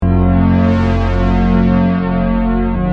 Free MP3 vintage Sequential circuits Pro-600 loops & sound effects 2